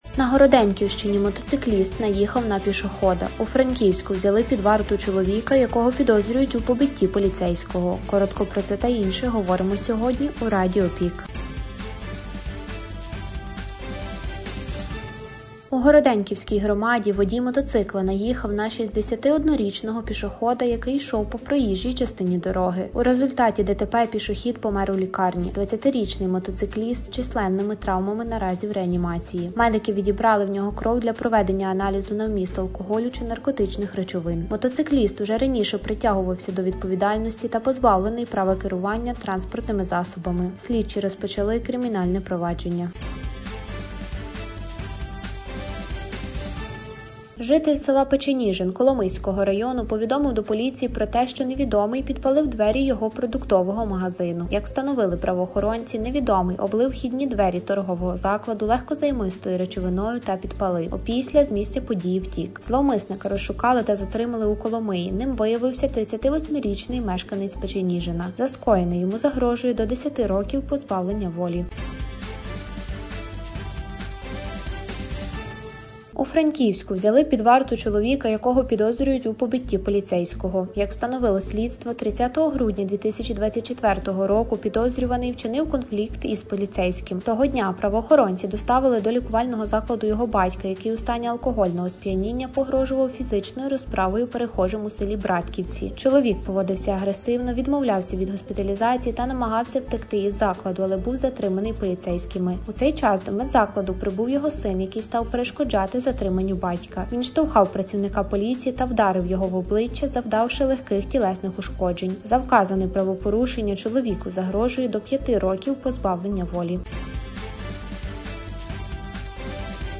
Пропонуємо Вам актуальне за день у радіоформаті.